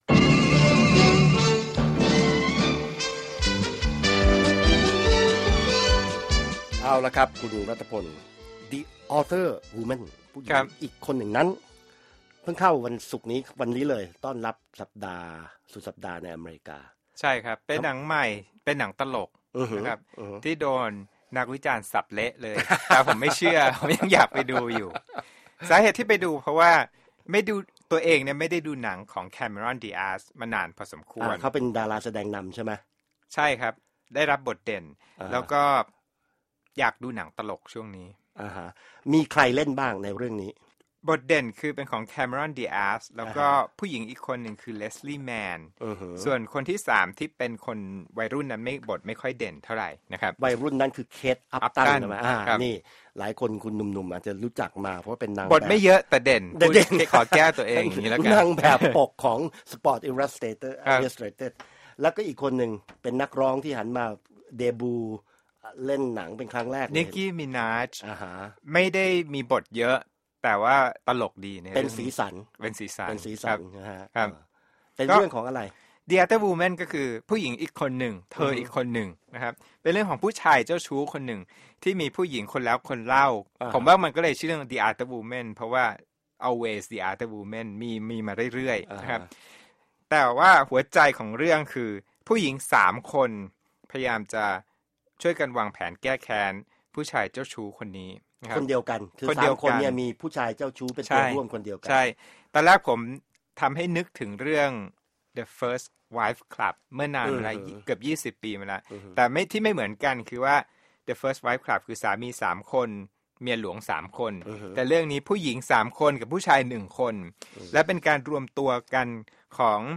Movie Review: The Other Woman